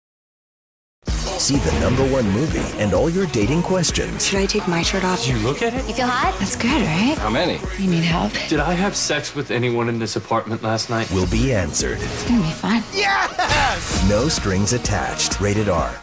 No Strings Attached TV Spots